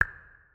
SFX_Dialog_03.wav